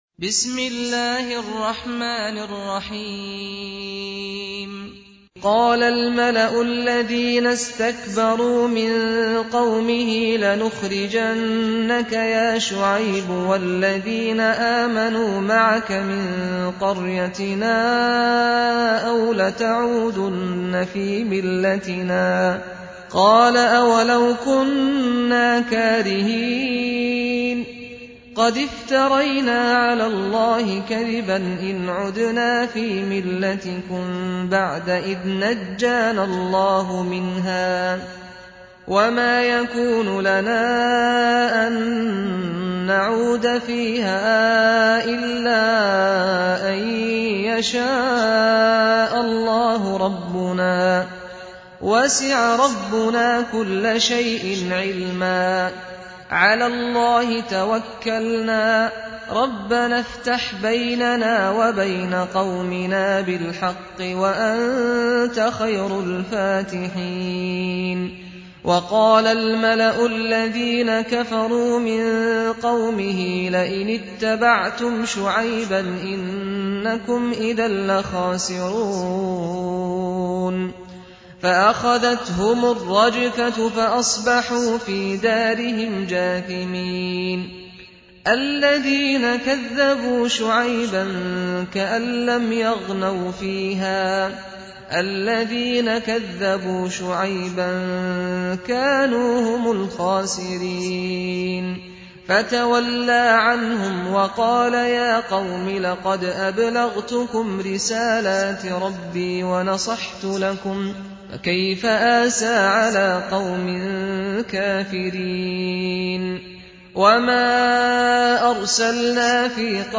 قرائت ترتیل «جزء 9» قرآن كریم با صدای استاد سعد الغامدی | به مدت 47 دقیقه
❖ دانلود ترتیل جزء نه قرآن کریم با صدای دلنشین استاد سعد الغامدی | مدت : 47 دقیقه